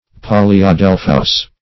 Search Result for " polyadelphous" : The Collaborative International Dictionary of English v.0.48: Polyadelphian \Pol`y*a*del"phi*an\, Polyadelphous \Pol`y*a*del"phous\, a. (Bot.)